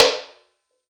CLAP - SLAP 2.wav